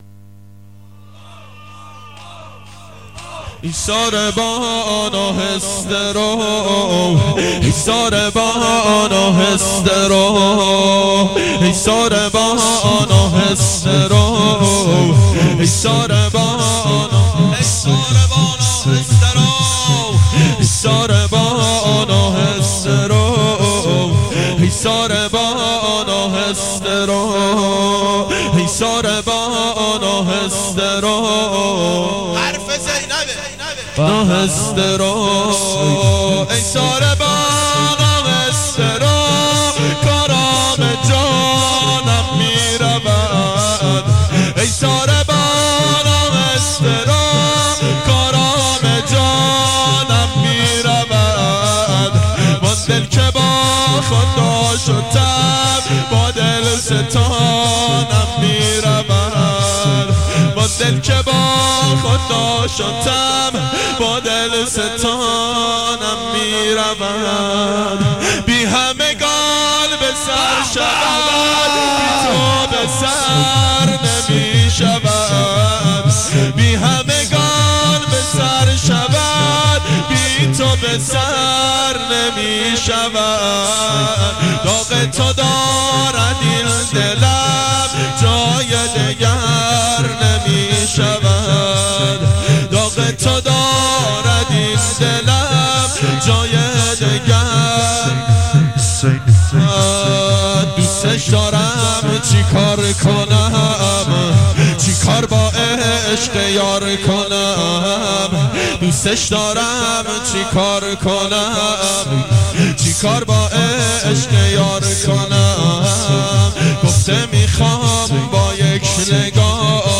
گلچین مداحی سال 1385